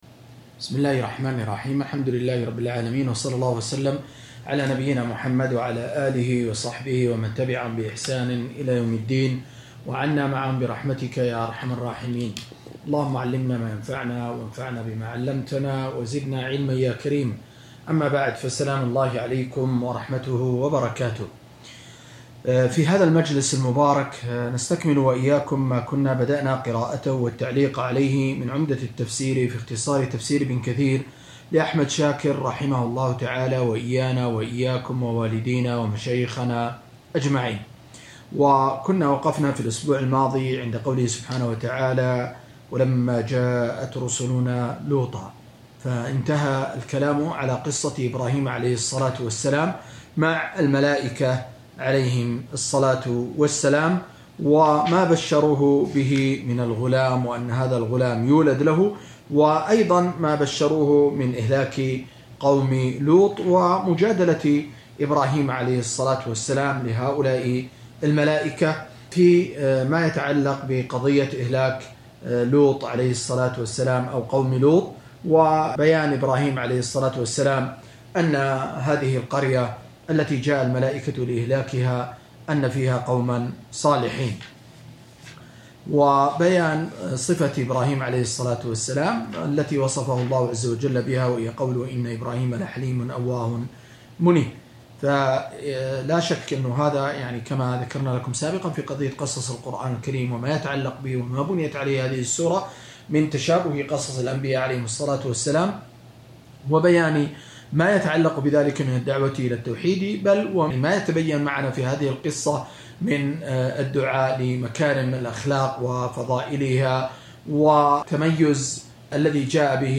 217- عمدة التفسير عن الحافظ ابن كثير رحمه الله للعلامة أحمد شاكر رحمه الله – قراءة وتعليق –